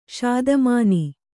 ♪ ṣādamāni